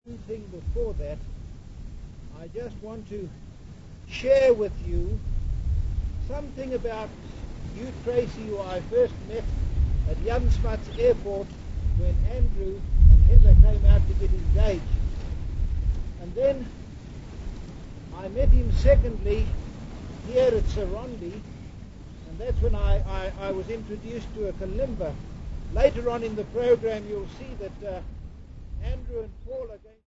ATC127a-01.mp3 of Hugh Tracey memorial service 1